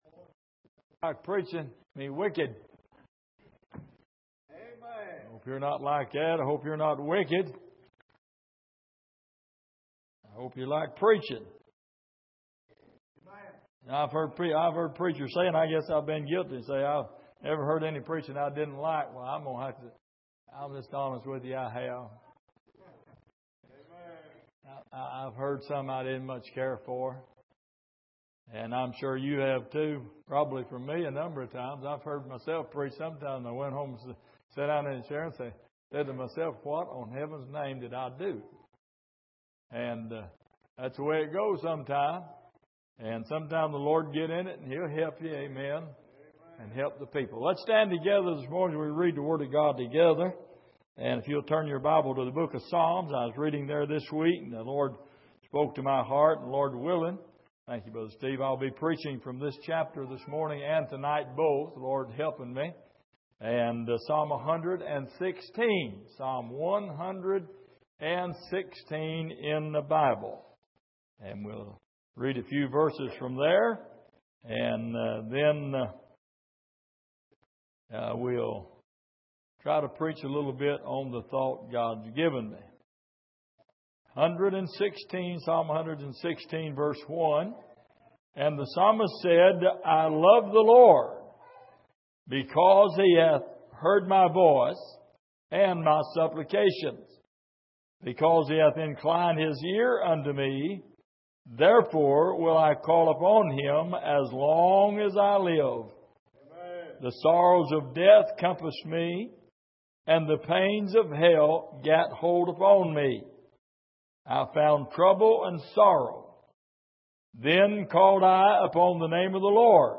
Passage: Psalm 116:1-9 Service: Sunday Evening